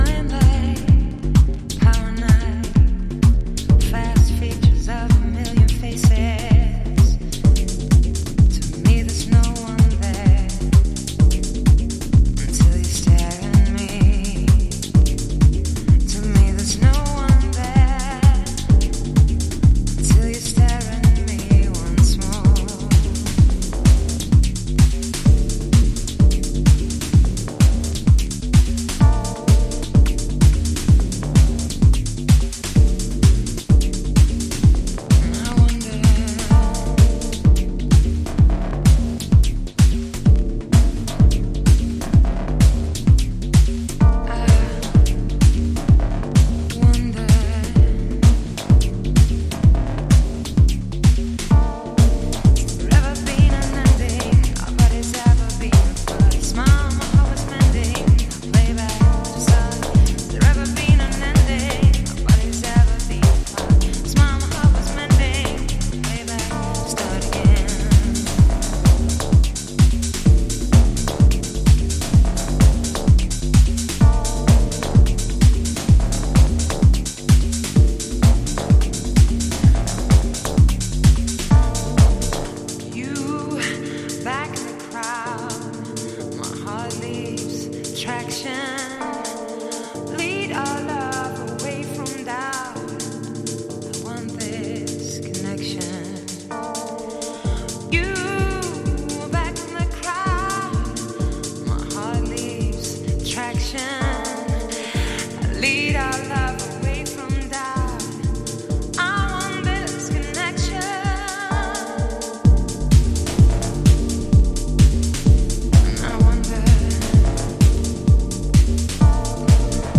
Detroit House / Techno